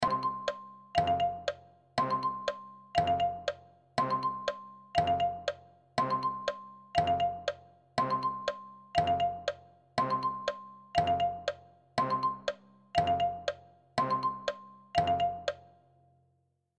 جلوه های صوتی
دانلود صدای ساعت 2 از ساعد نیوز با لینک مستقیم و کیفیت بالا